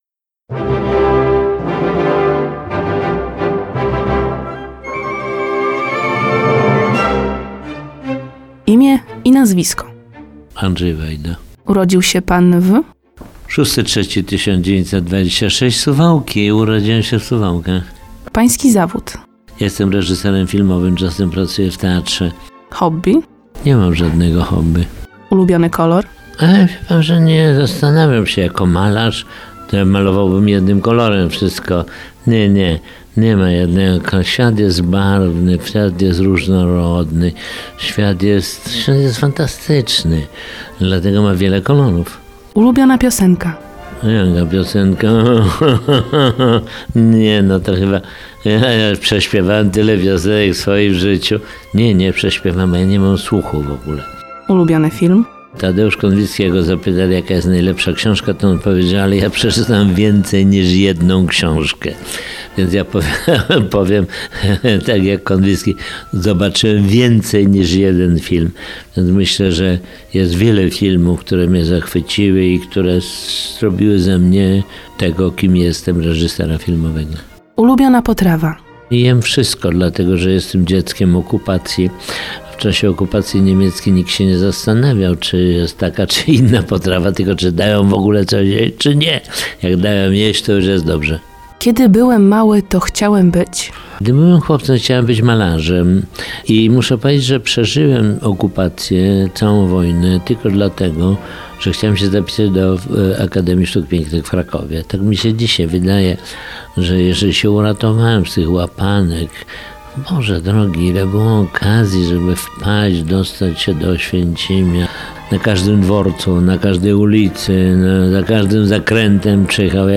W poniedziałek (09.10) o 18:15 w Radiu 5 przypomnimy archiwalny wywiad z Andrzejem Wajdą.